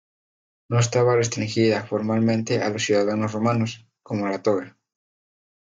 Pronounced as (IPA) /ˈtoɡa/